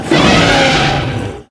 hurt4.ogg